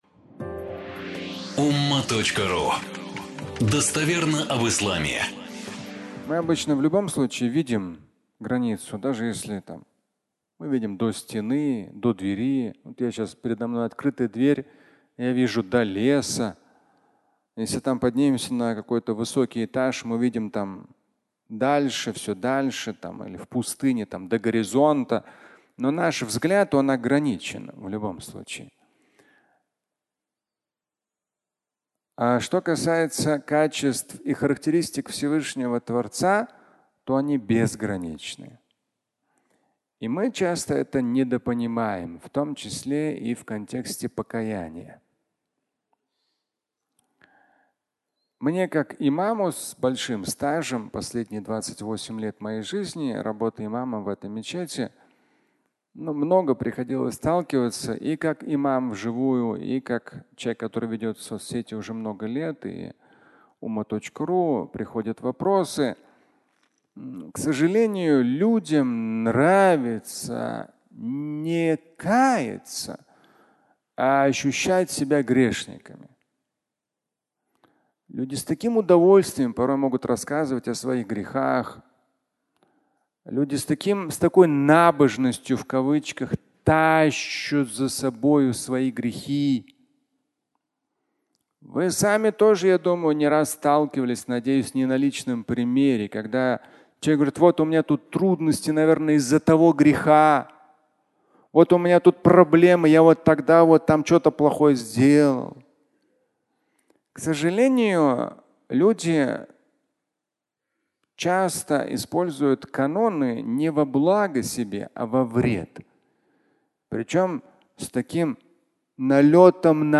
Бескрайний простор (аудиолекция)